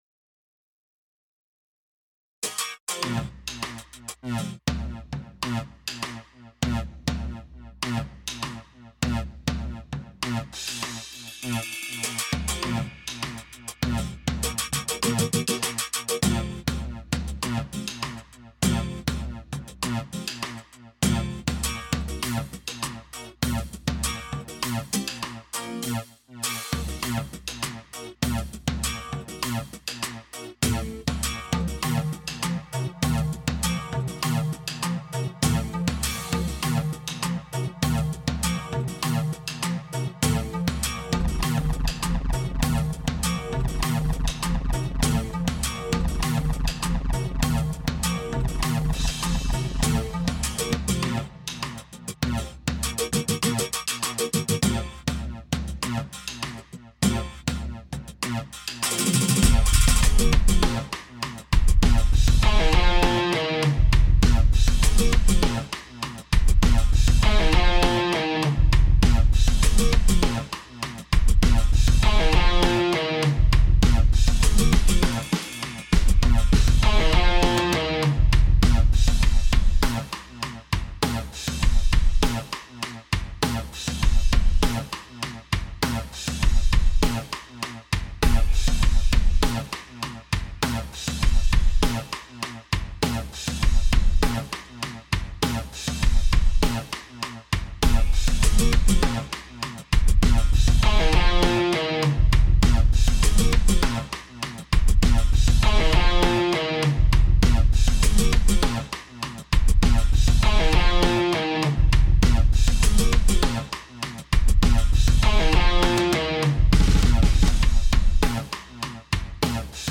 Dann noch ein Stück, in dem mehrere Instrumente aus eXplorer 5 vorkommen.
eXplorer 5 Multi Instrumente: Darin enthalten sind der Go2, RG, blue2, SubBoomBass. Ausserdem noch die Rock Standard Gitarre von Orange Tree Samples und die Addictive Drums von XLN Audio.